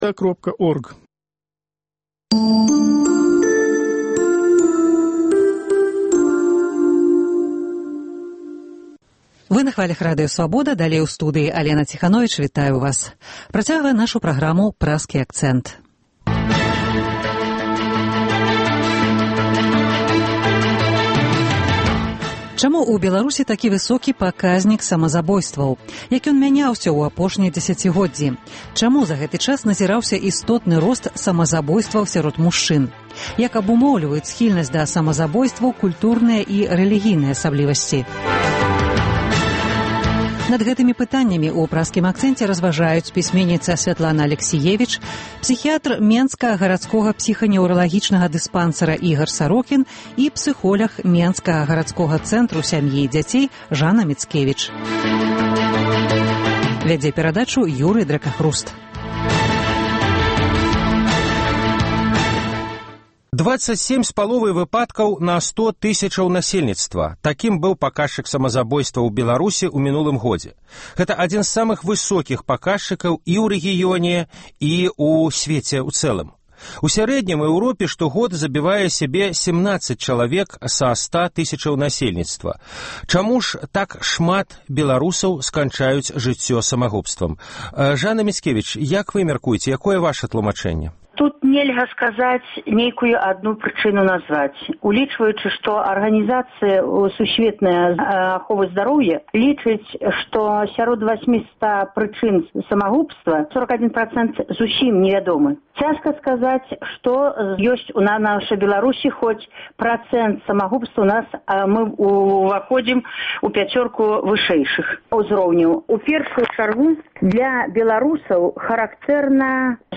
Як абумоўліваюць схільнасьць да самазабойстваў культурныя і рэлігійныя асаблівасьці? Над гэтымі пытаньнямі ў “Праскім акцэнце” разважаюць пісьменьніца Сьвятлана Алексіевіч